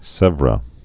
(sĕvrə)